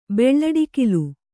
♪ beḷḷaḍikilu